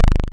clack.wav